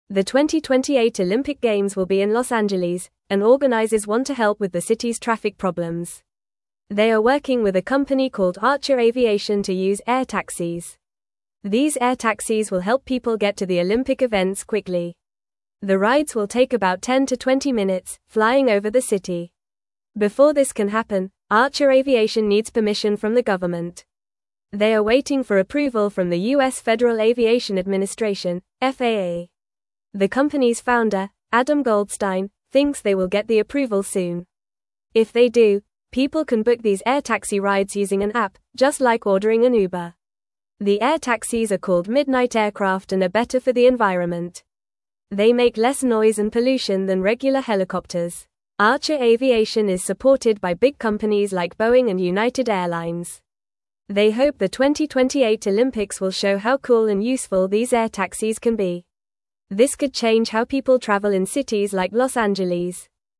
Fast
English-Newsroom-Lower-Intermediate-FAST-Reading-Flying-Taxis-Could-Help-at-the-2028-Olympics.mp3